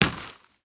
Dirt footsteps 14.wav